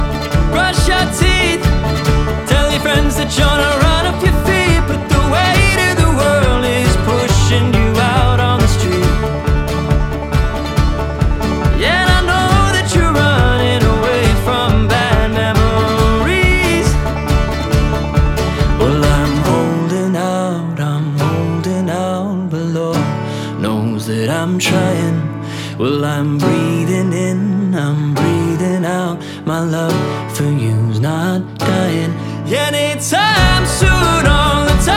Акустические струны и тёплый вокал
Folk Pop
Жанр: Поп музыка / Фолк